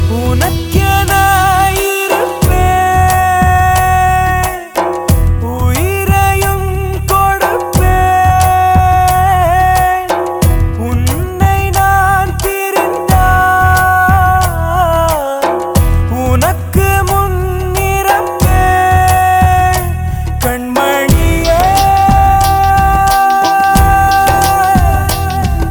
best flute ringtone download
sad ringtone download